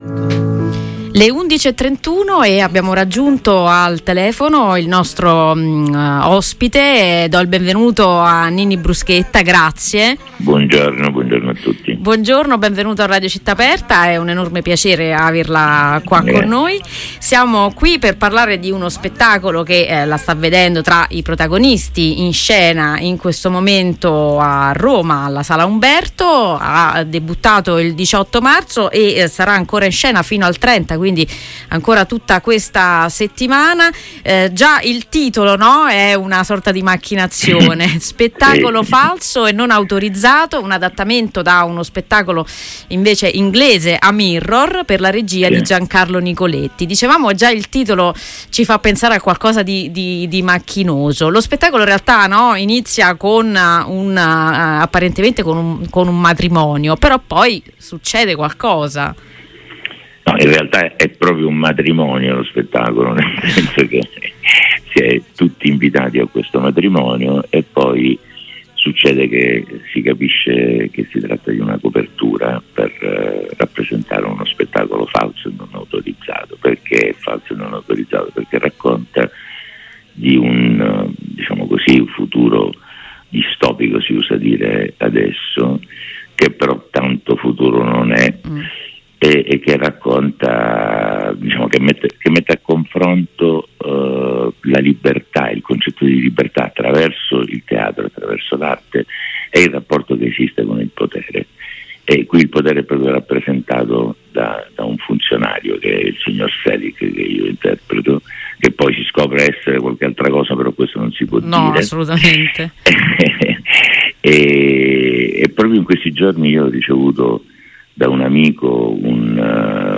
Intervista a Ninni Bruschetta del 24 Marzo